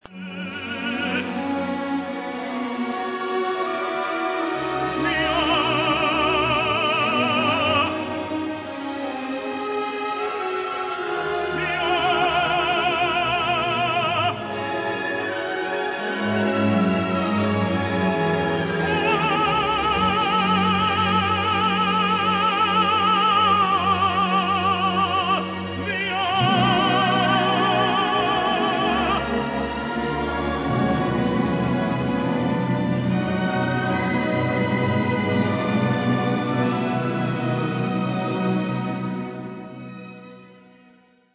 Il a personnellement soif de justice, d’où l’absolue perfection de la 4e Béatitude, où la tension va implacablement crescendo jusqu’à la délivrance par la parole du Christ : la prière, qui culmine par l’ardente répétition du mot